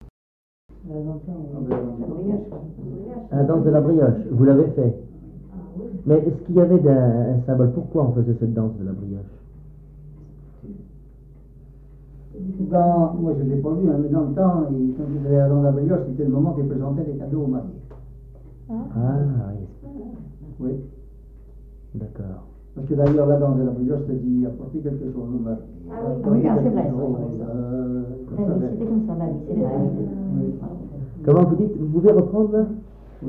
Témoignages sur les noces et les danses
Catégorie Témoignage